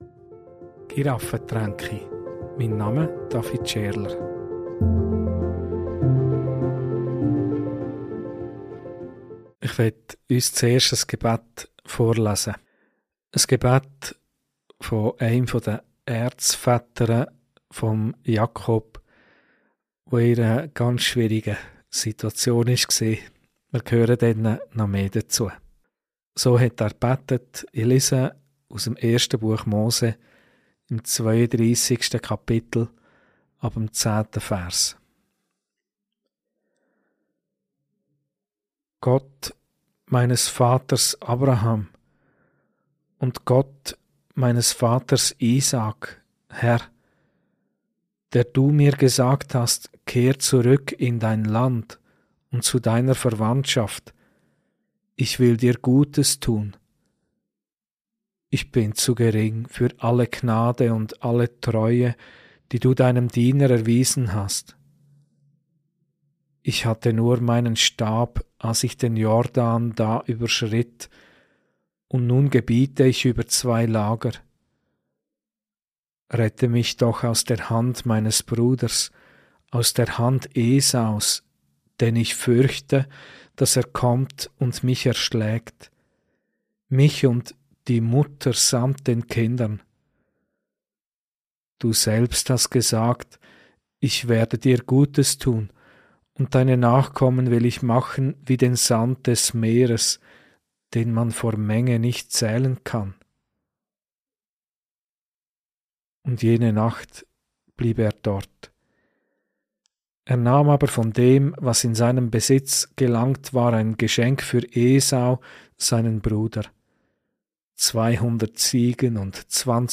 Predigt - Mit Jakob Gottvertrauen lernen ~ Giraffentränke Podcast